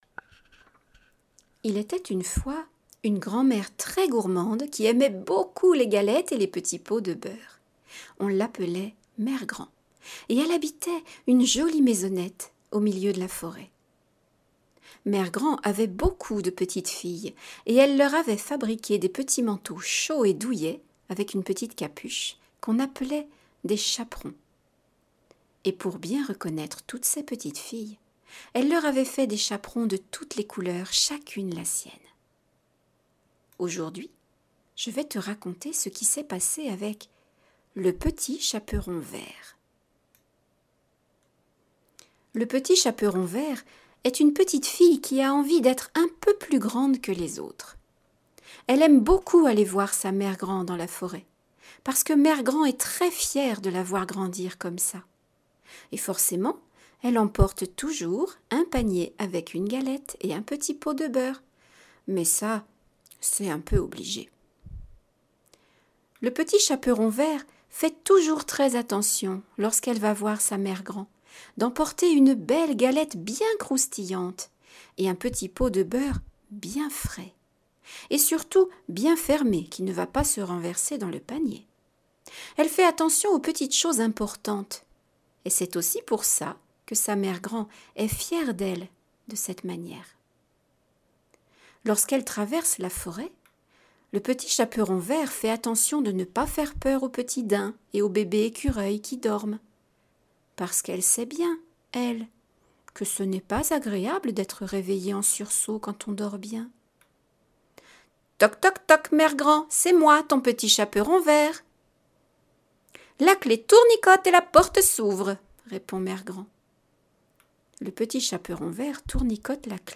Histoires audio pour tous à écouter en rêvant